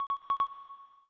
Upload test sfx